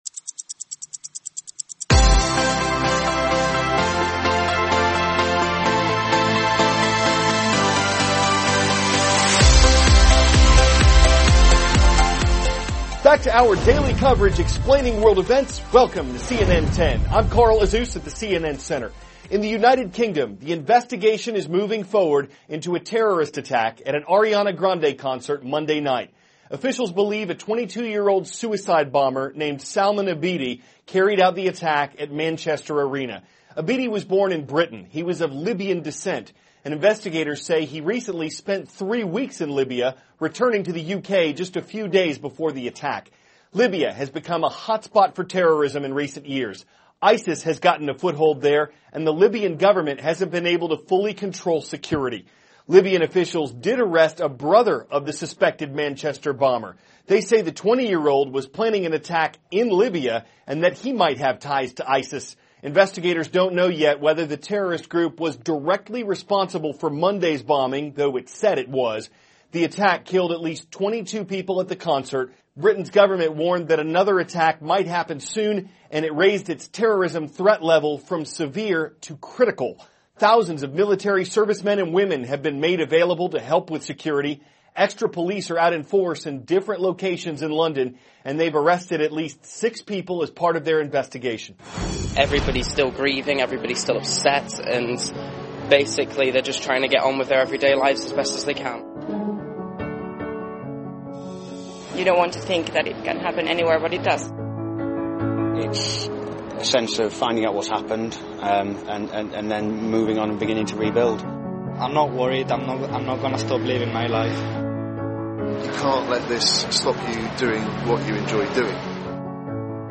*** CARL AZUZ, cnn 10 ANCHOR: Back to our daily coverage explaining world events.